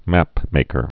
(măpmākər)